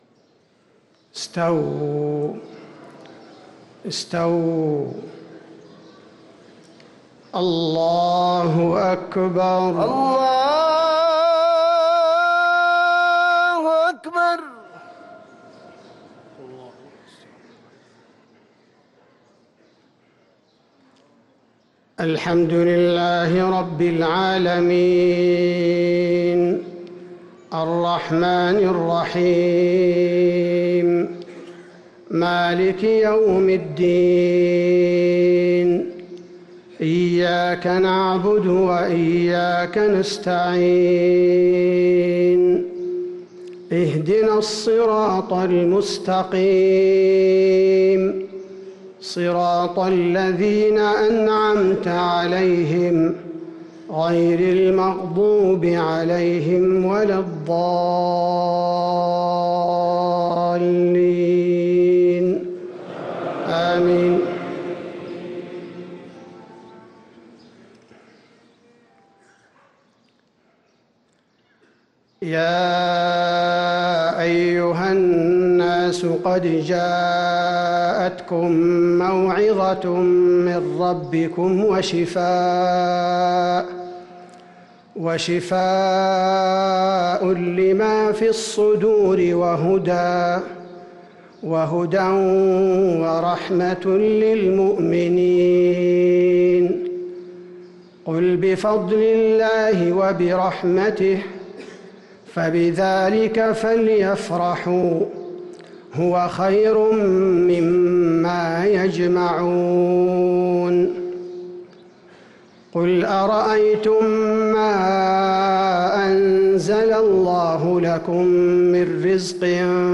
صلاة الفجر للقارئ عبدالباري الثبيتي 29 رمضان 1444 هـ